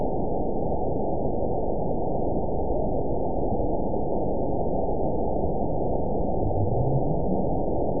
event 920389 date 03/22/24 time 22:56:04 GMT (1 year, 1 month ago) score 9.51 location TSS-AB02 detected by nrw target species NRW annotations +NRW Spectrogram: Frequency (kHz) vs. Time (s) audio not available .wav